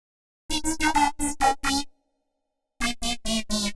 Index of /musicradar/uk-garage-samples/128bpm Lines n Loops/Synths